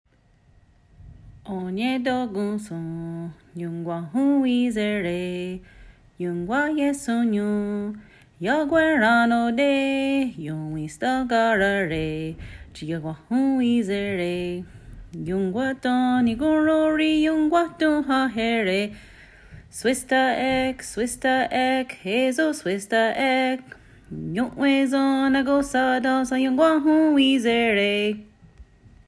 Our Music